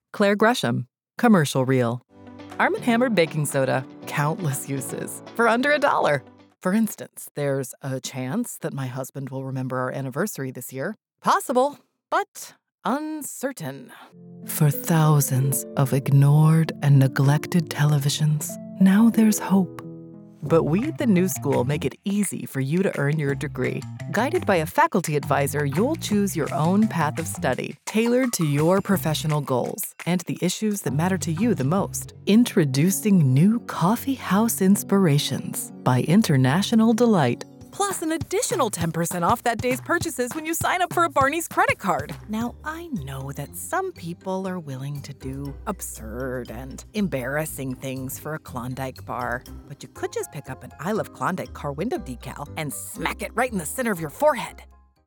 Commercial Reel 55s
General American, US Southern (various dialects), British RP
Middle Aged